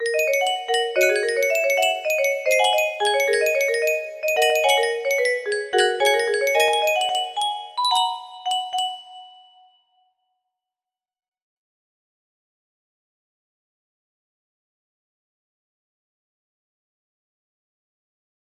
oeq3wgujiow3q2gt music box melody